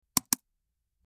Mouse Click 03
Mouse_click_03.mp3